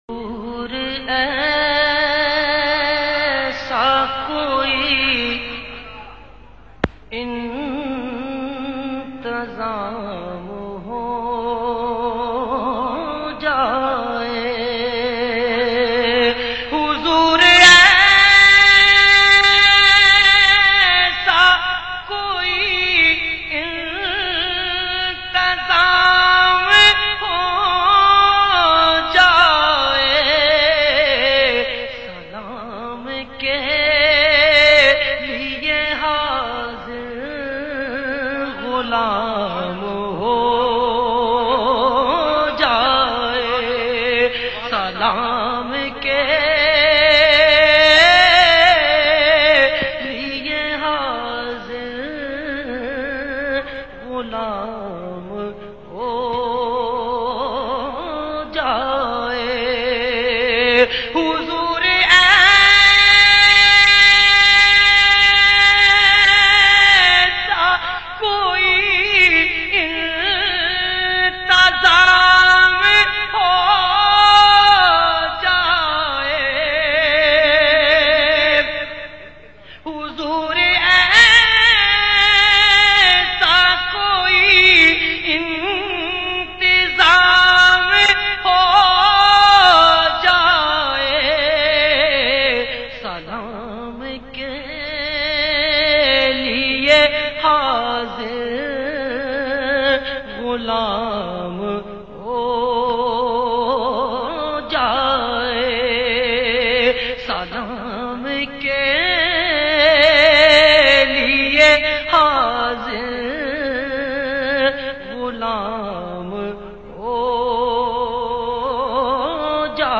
in best audio quality